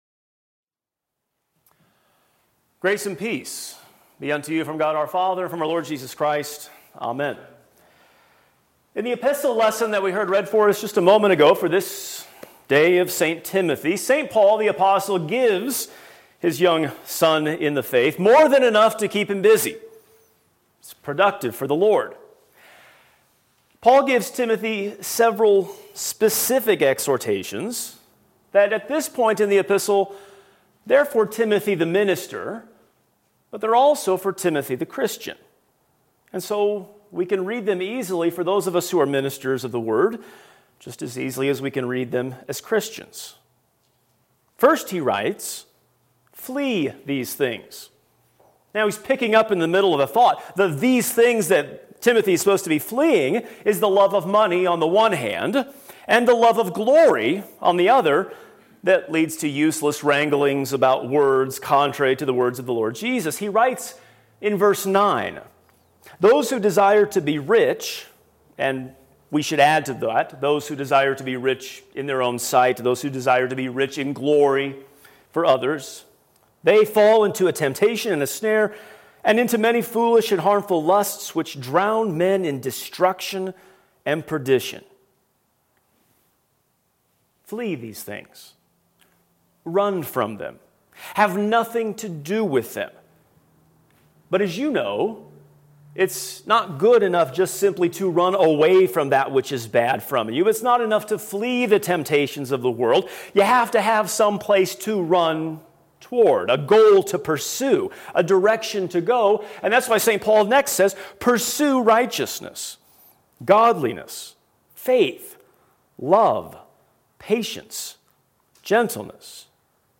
Sermon (audio)